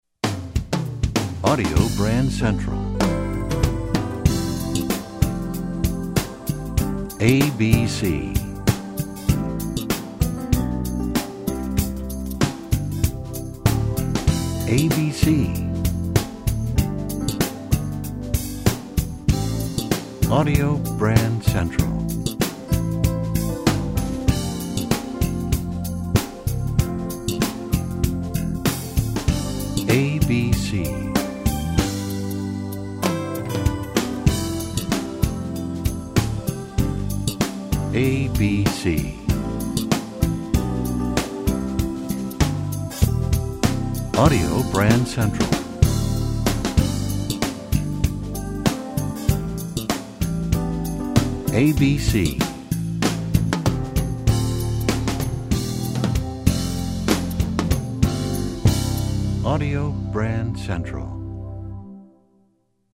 MCM Category: Radio Jingles